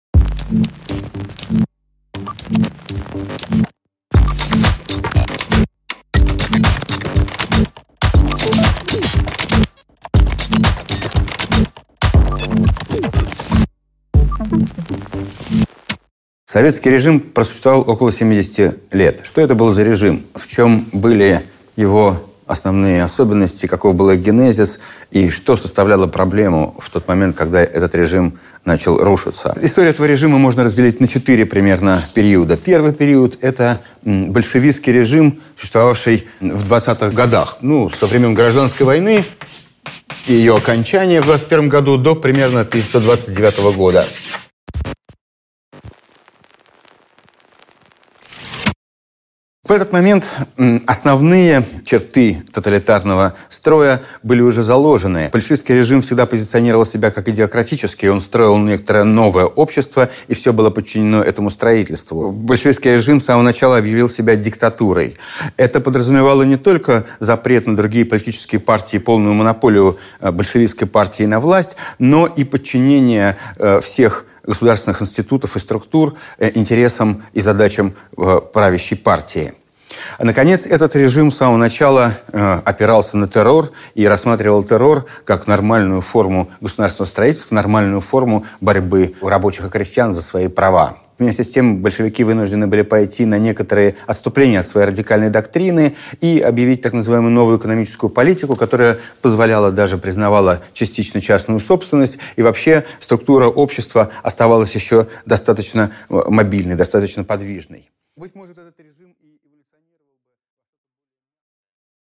Аудиокнига От террора до маразма: система Сталина и ее закат | Библиотека аудиокниг